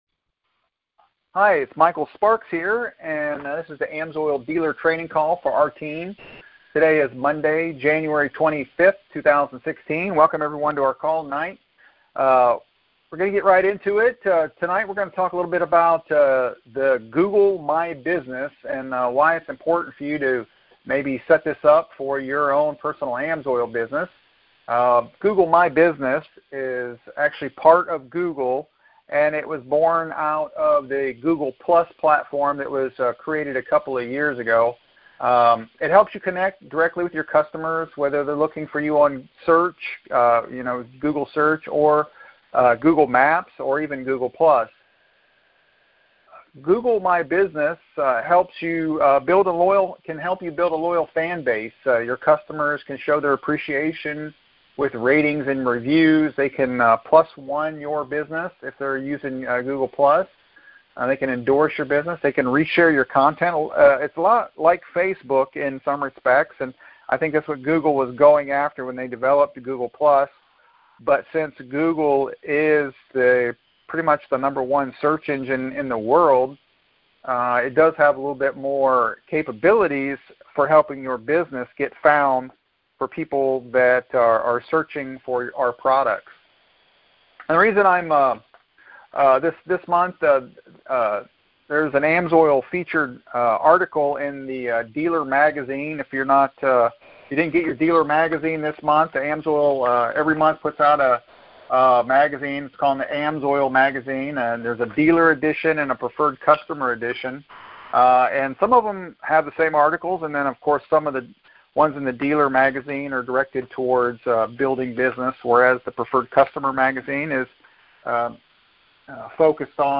Sparks Team AMSOIL Dealer Training Call |January 25th, 2016